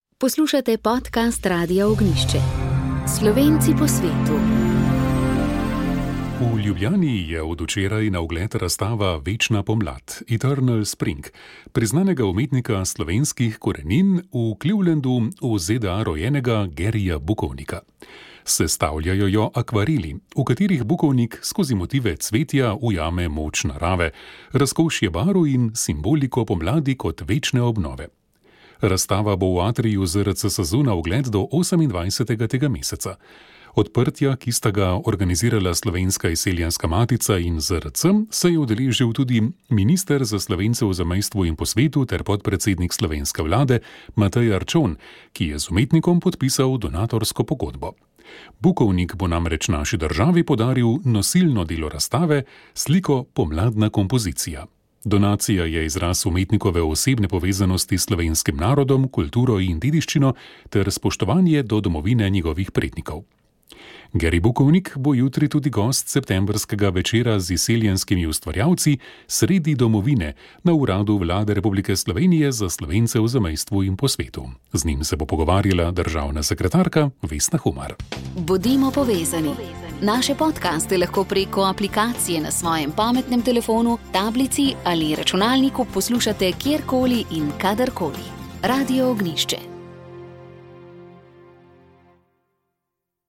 Gostili smo Jelko Godec, SDS, Jožeta Horvata, NSI in Zmaga Jelinčiča, SNS. Kritično so ocenili dosedanje delo vlade, zlasti ideje o ukinitvi dopolnilnega zdravstvenega zavarovanja, neambiciozno pokojninsko reformo in zamujanje pri infrastrukturnih projektih.